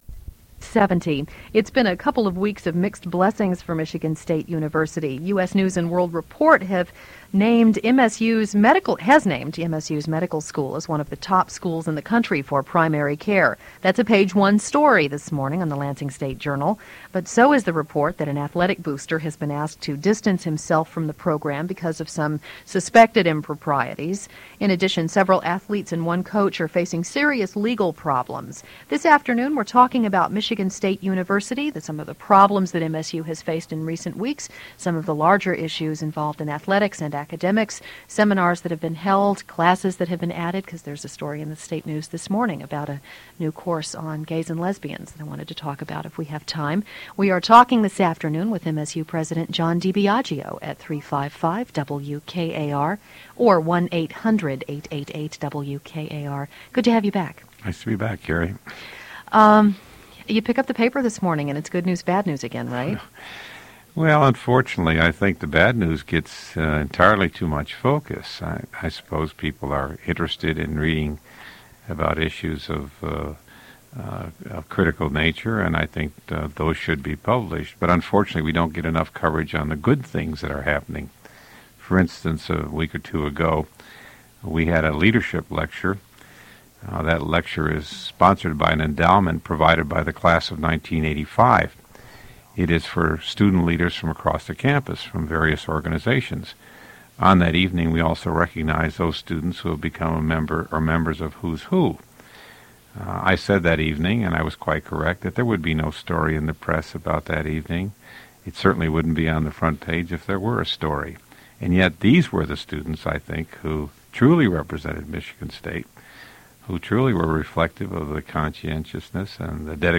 Radio Interview
Original Format: Audio cassette tape